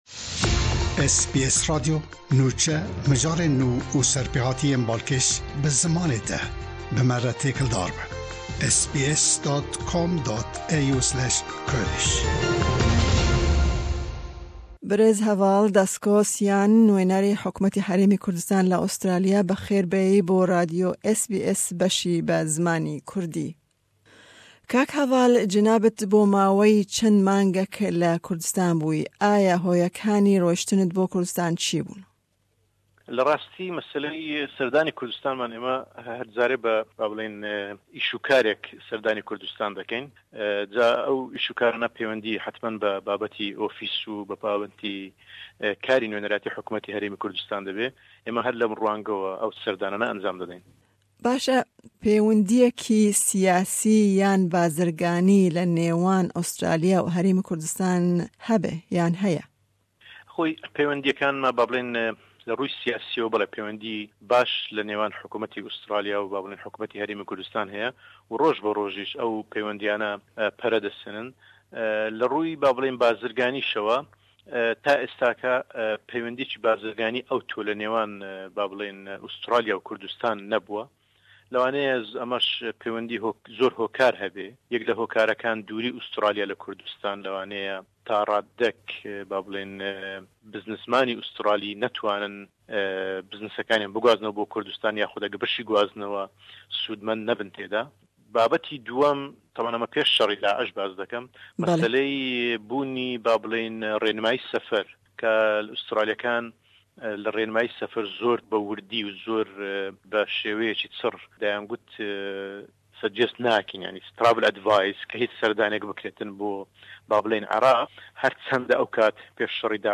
Me hevpeyvînek bi Heval Dasko Syan nûnerê Hukmeta Herêma Kurdistanê li Australya sebaret bi serdana wî bo Kurdistanê û pêwindiyên Australya tev HHK pêk anî.